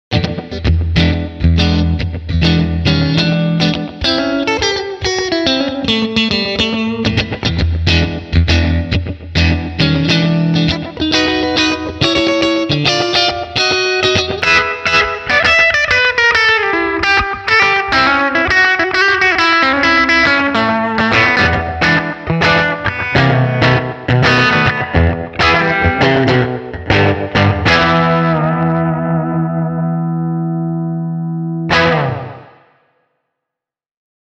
Soundi saa keskiväkevästä humbuckerista selvästi enemmän ryhtiä, potkua ja paksuutta.
Äänipätkissä soi aina ensin Tokain kaula- ja keskimikrofoni yhdessä, minkä jälkeen siirrytään Seymour Duncaniin: